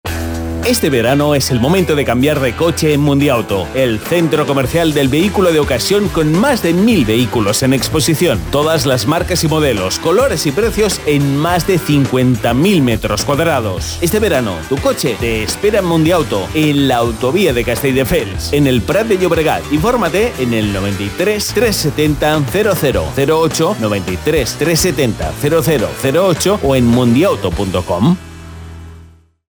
kastilisch
Sprechprobe: Industrie (Muttersprache):